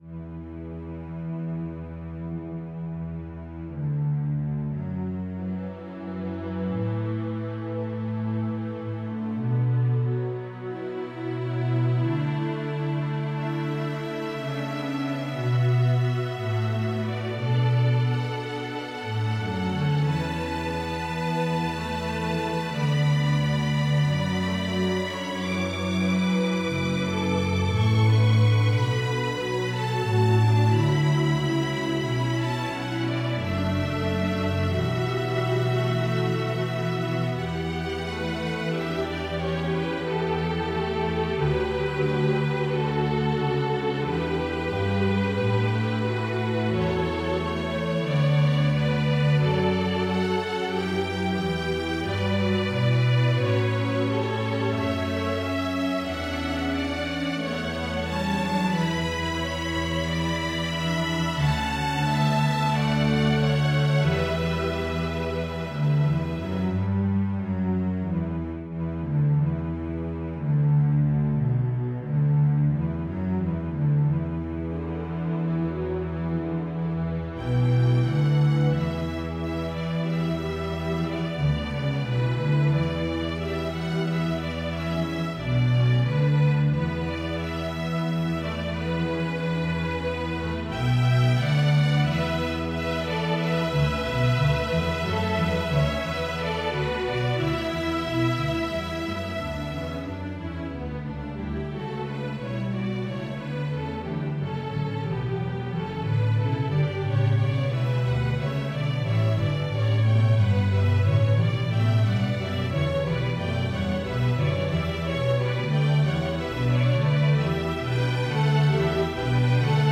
The current version is a fantasia and choral arrangement thereof, but I have included the SATB choral arragement as well.